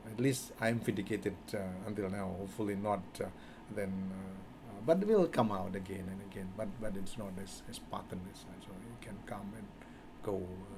S1 = Indonesian male S2 = Malaysian female
The use of a back vowel rather than [æ] in the first syllable of pattern was part of the problem.